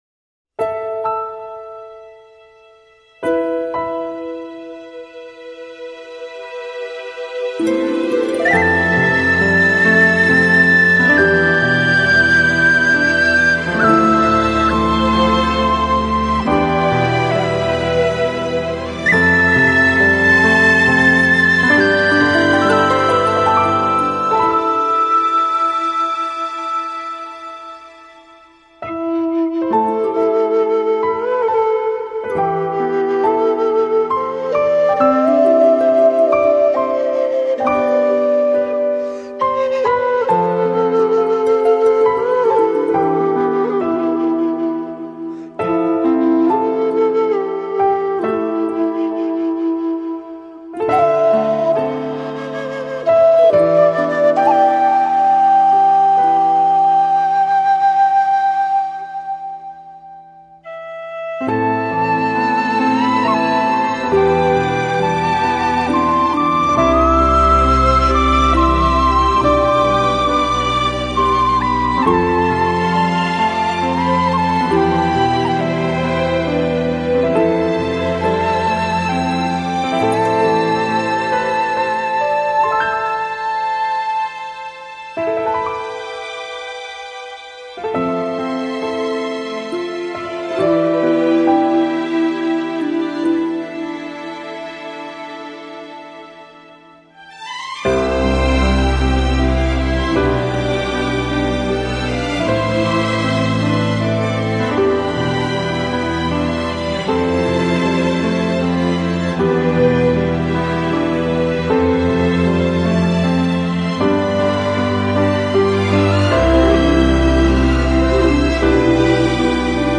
معنوی بودنشو نمیدونم ولی شاید ارامش بخش باشه!